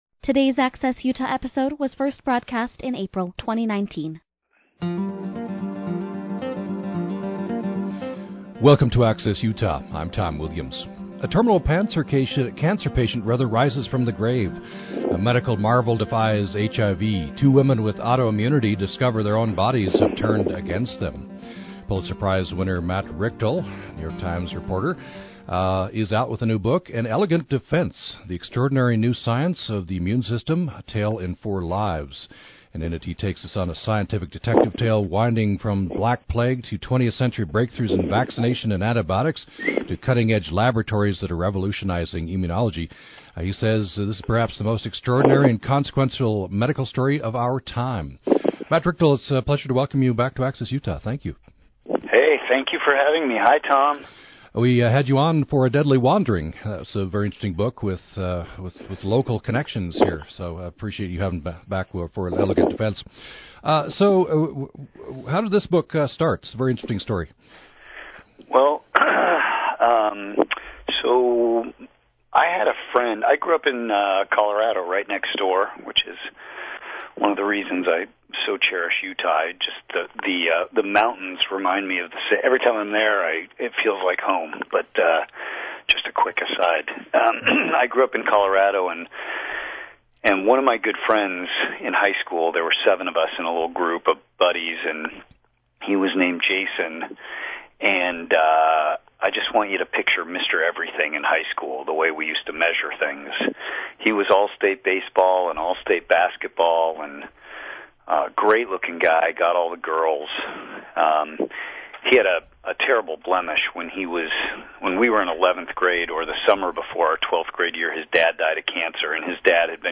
The hour-long show airs live Monday-Thursday at 9:00 a.m. Access Utah covers everything from pets to politics in a range of formats from in-depth interviews to call-in shows.